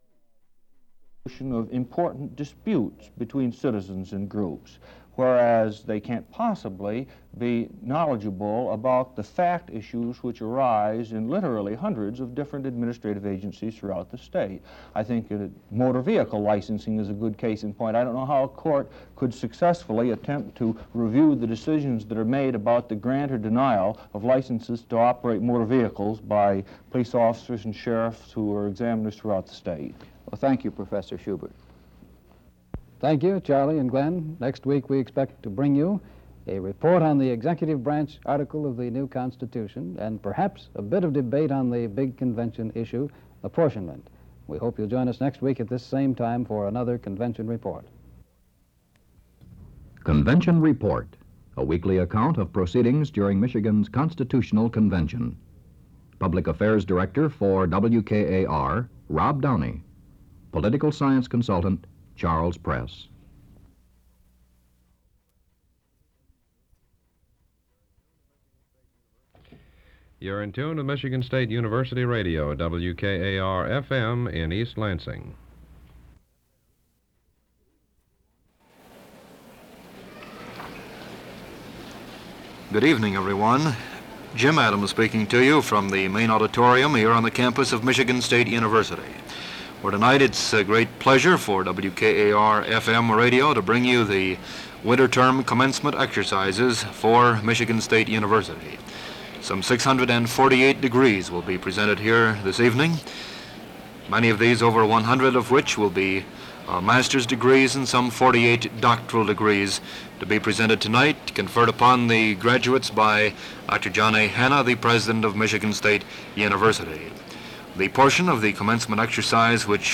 The commencement speaker is Stephen Sutherland Nisbet, President of the Michigan Constitutional Convention.
NOTE: This recording ends abruptly.